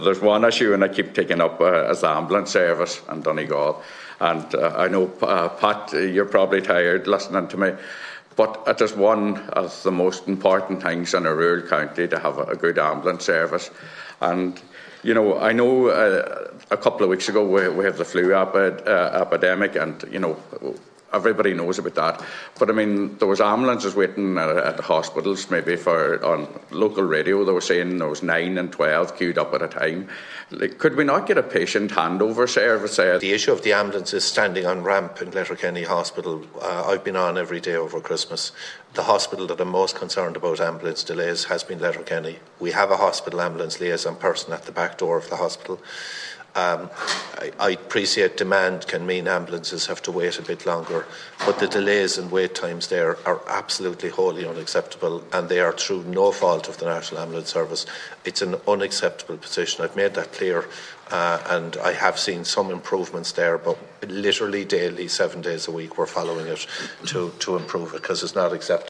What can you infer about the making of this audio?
He told the meeting that work is ongoing on a daily basis to improve the situation at the hospital: